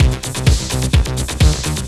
TECHNO125BPM 19.wav